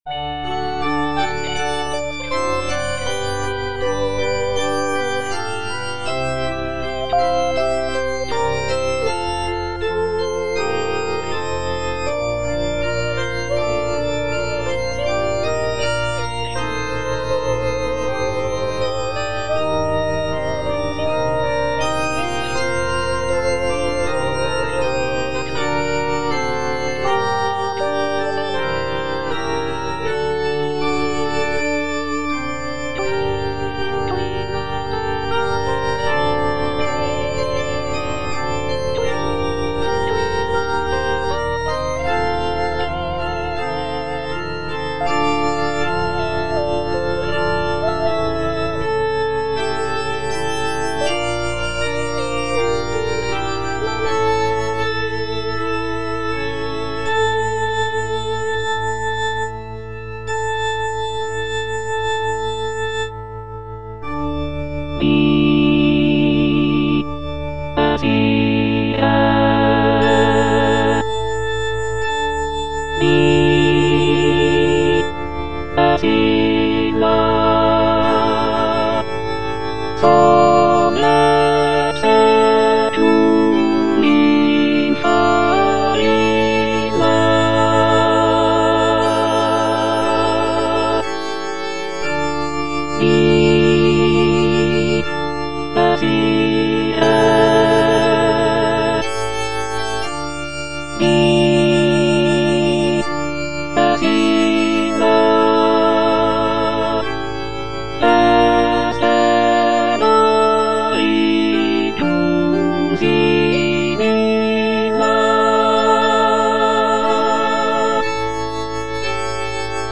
Alto (Emphasised voice and other voices) Ads stop